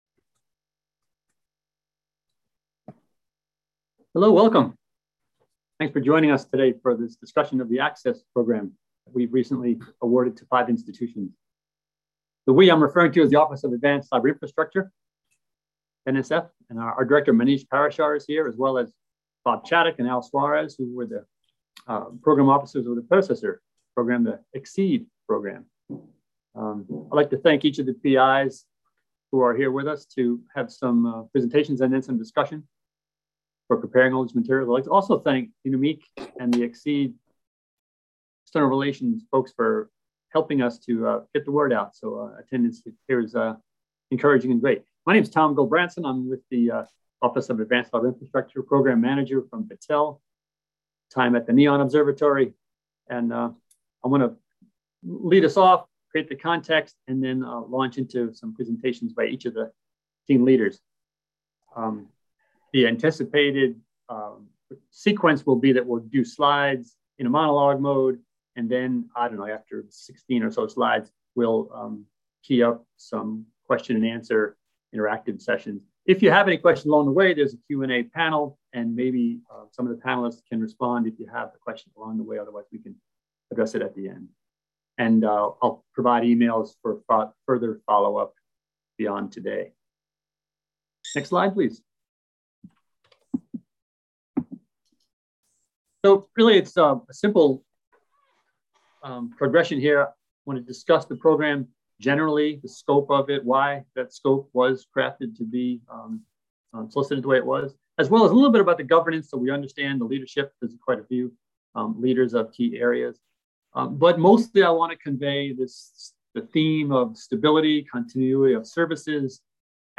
ACCESS Public Town Hall Webinar